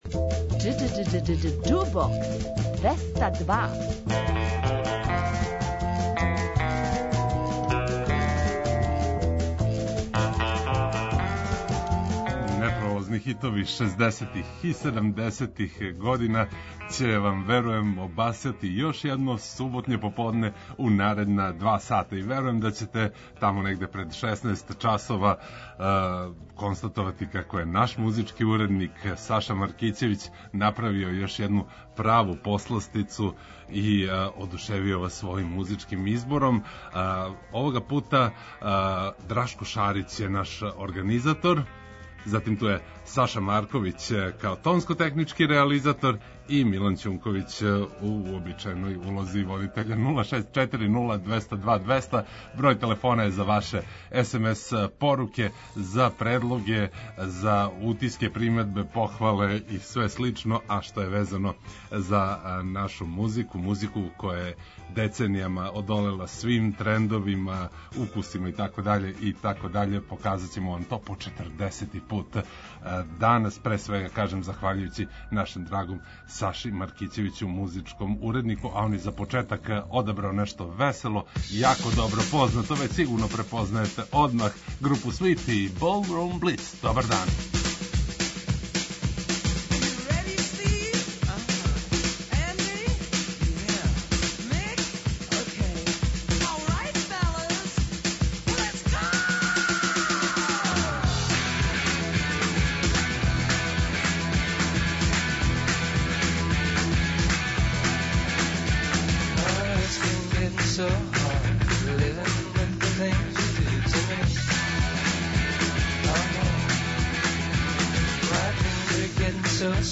преузми : 27.77 MB Џубокс 202 Autor: Београд 202 Уживајте у пажљиво одабраној старој, страној и домаћој музици.